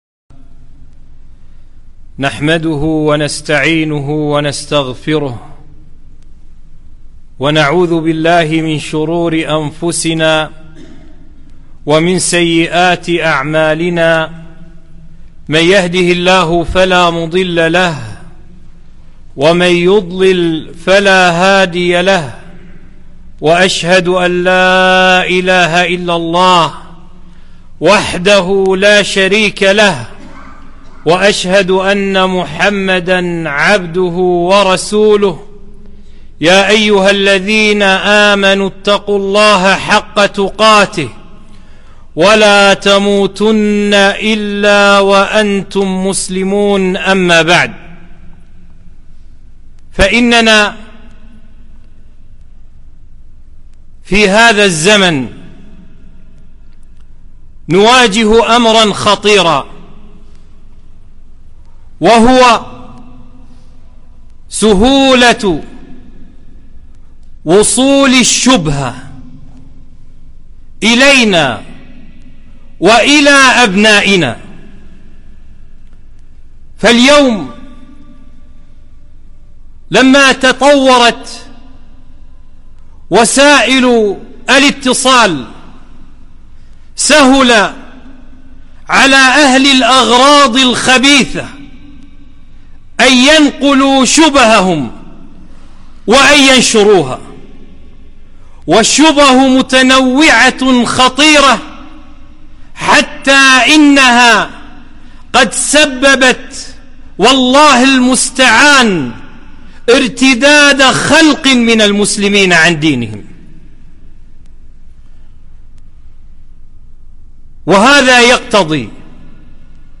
خطبة - أدلة وبراهين صدق نبوة محمد صلى الله عليه وسلم